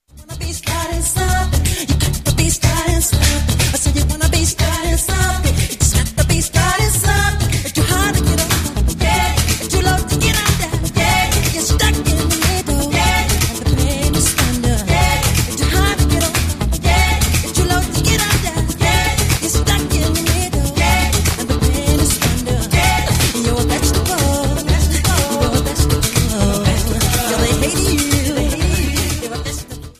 Pop & Rock
acustic demo version